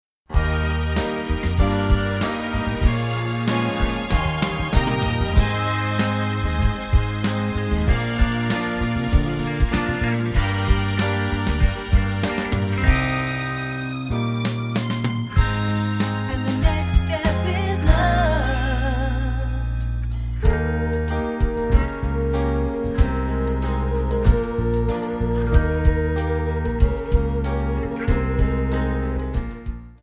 Backing track files: 1970s (954)
Buy With Backing Vocals.